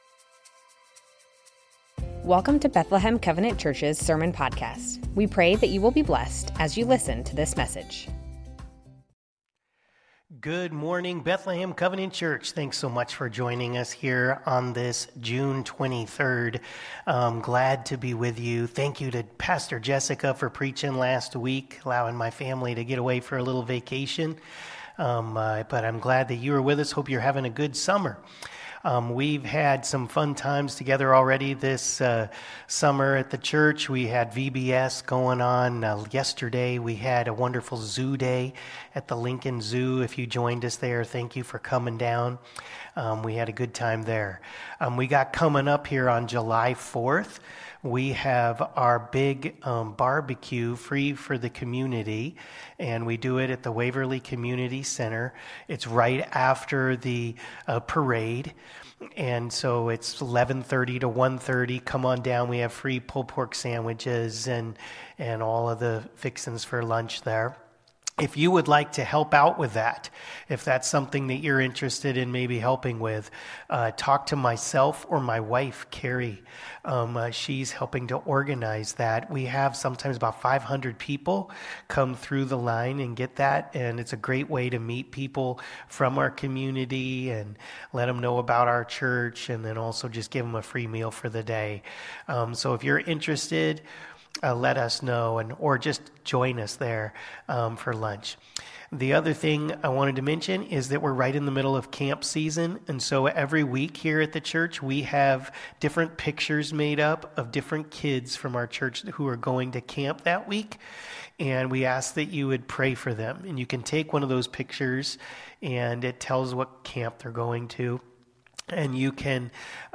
Bethlehem Covenant Church Sermons James - The Lord's Will Jun 23 2024 | 00:30:40 1x 00:00 / 00:30:40 Subscribe Share Spotify RSS Feed Share Link Embed